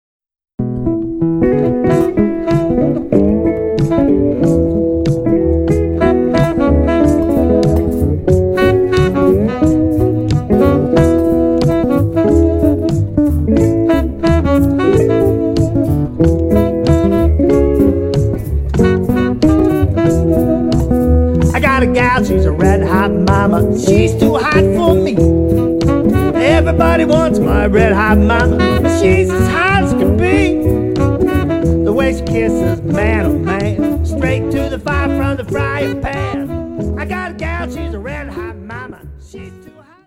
Nice upbeat tempo.